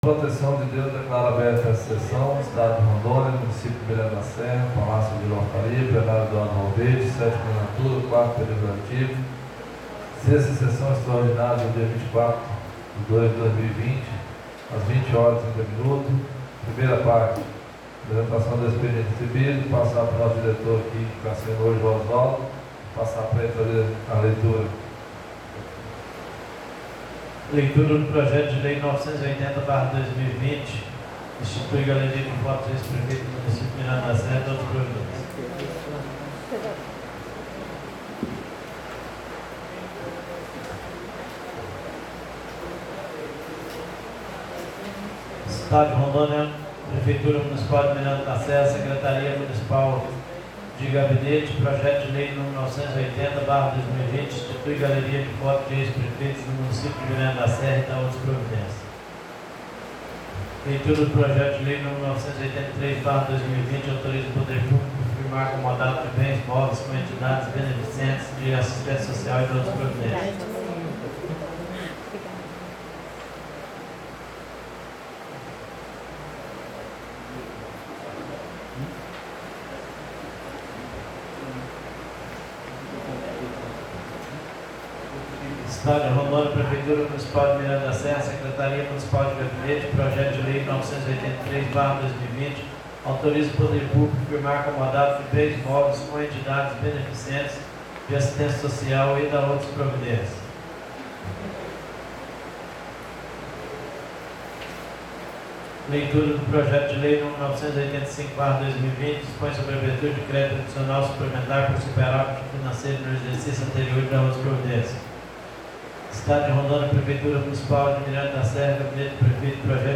Sessão Extraordinária